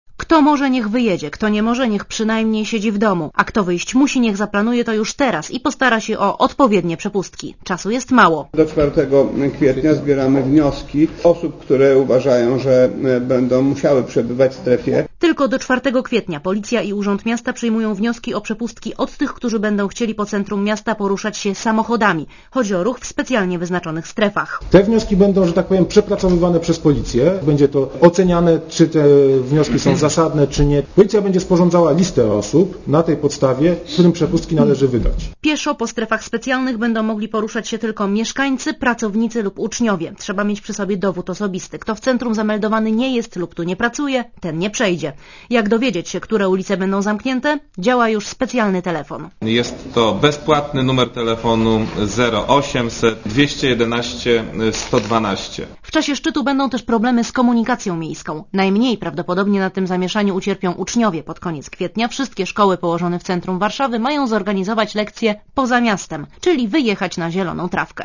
Posłuchaj relacji reporterki Radia Zet (264 KB)